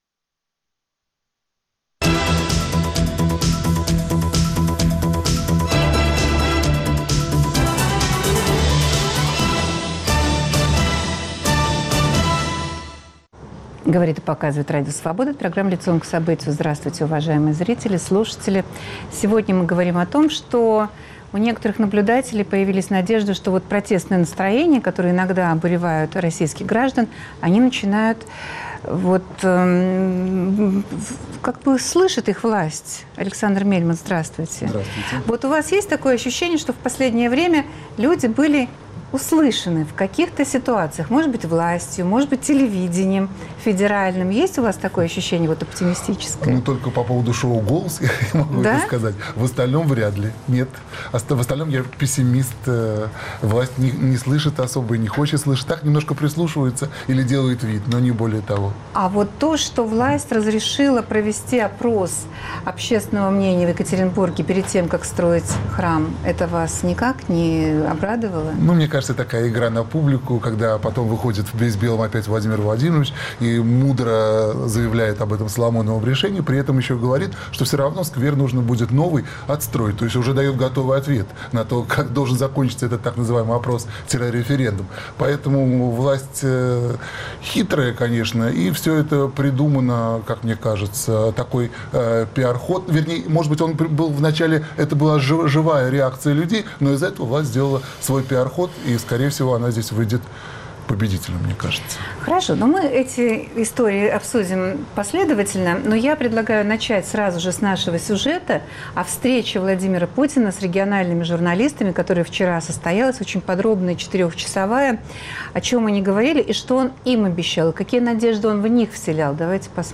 А также: что понял о стране Владимир Путин из общения в Сочи с региональными журналистами? В студии